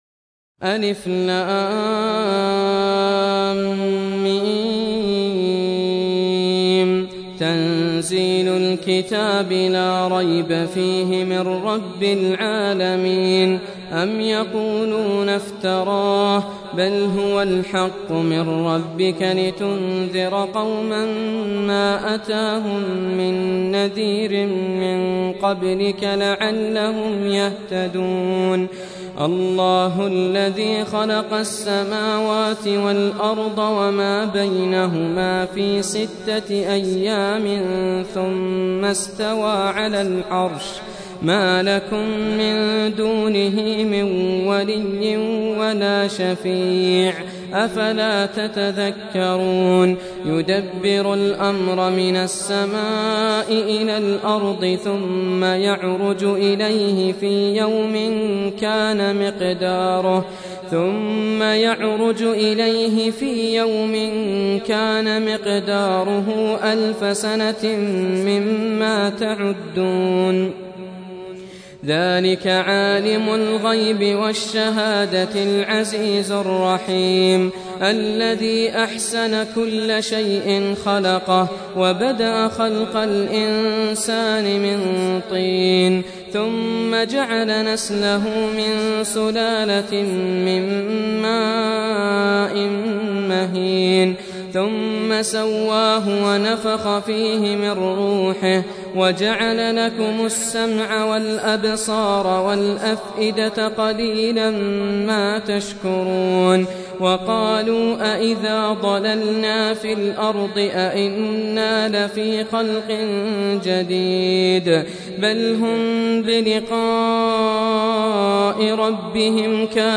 Surah Repeating تكرار السورة Download Surah حمّل السورة Reciting Murattalah Audio for 32. Surah As�Sajdah سورة السجدة N.B *Surah Includes Al-Basmalah Reciters Sequents تتابع التلاوات Reciters Repeats تكرار التلاوات